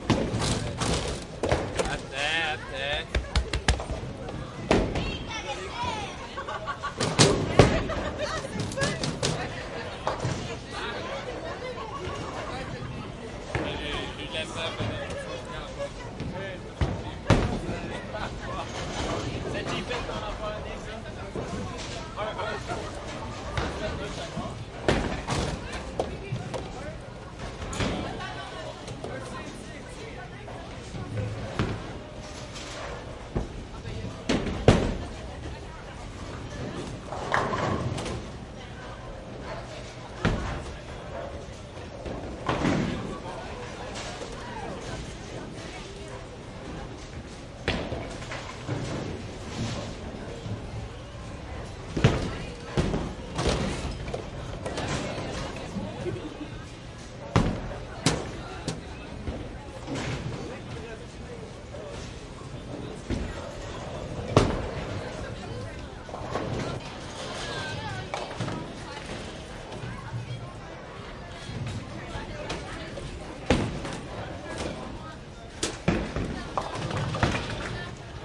蒙特利尔 " 人群中进入剧院，安顿下来的魁北克人的声音 蒙特利尔，加拿大
描述：人群int媒体进入剧院，安顿下来魁北克人的声音蒙特利尔，Canada.flac
标签： 蒙特利尔 魁北克 进入 下来 声音 INT 人群 戏剧 加拿大 定居
声道立体声